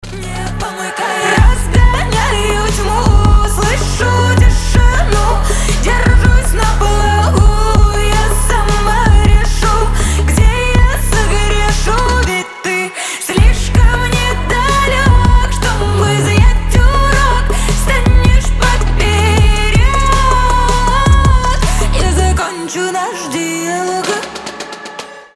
• Качество: 320, Stereo
атмосферные
красивый женский голос